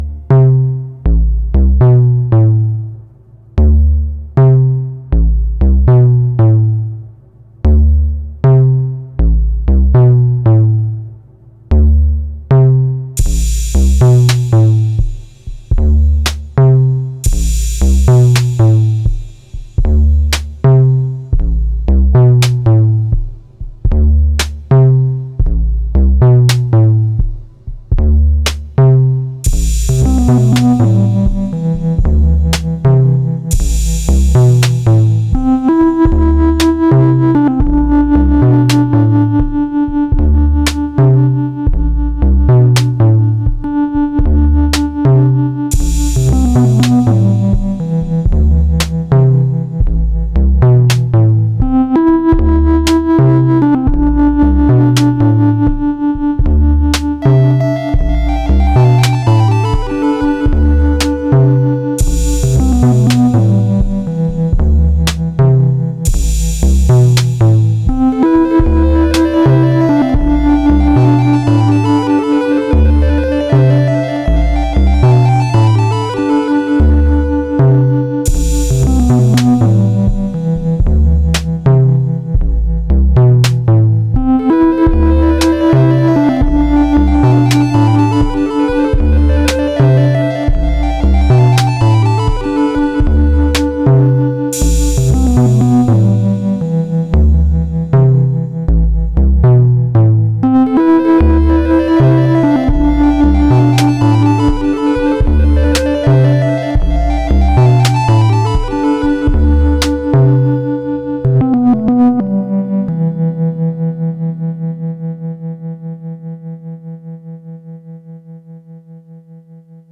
this is just rytm with external keyboard